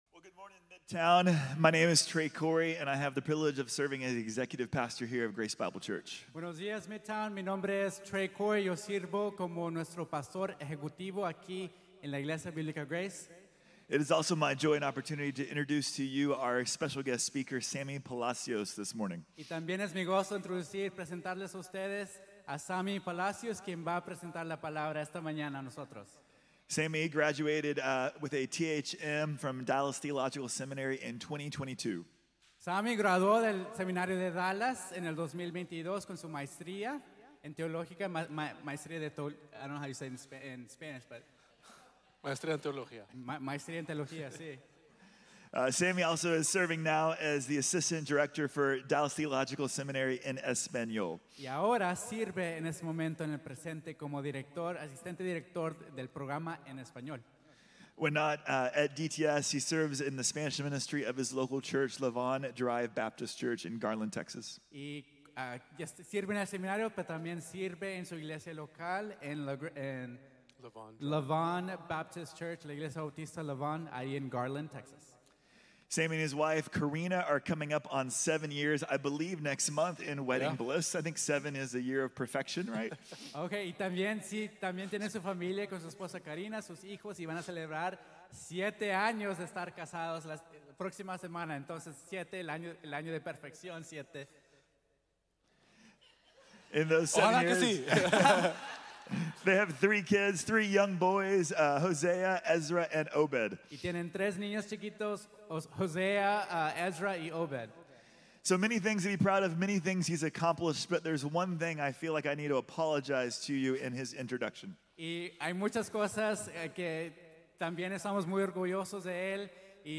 Reconociendo al Proveedor | Sermón | Iglesia Bíblica de la Gracia